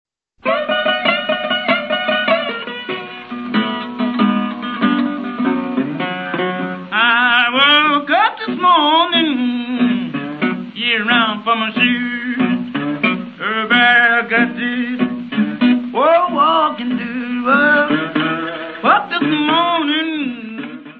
: stereo; 12 cm
Área:  Jazz / Blues